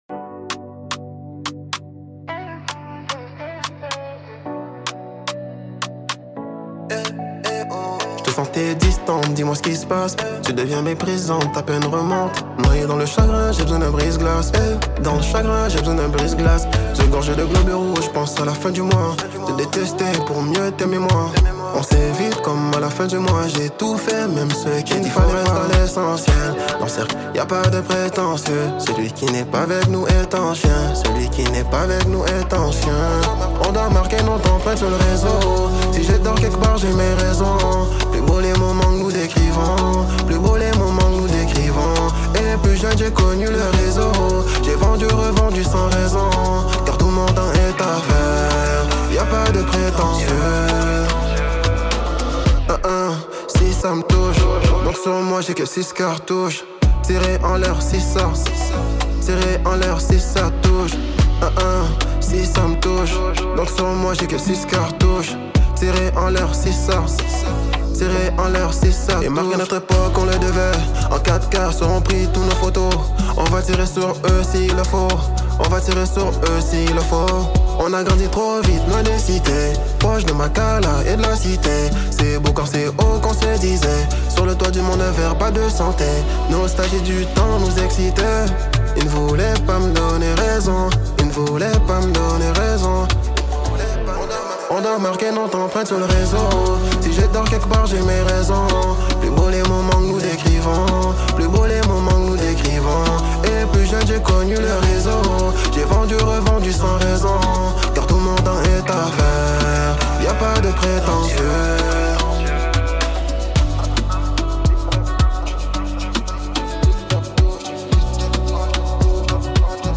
introspectif et fédérateur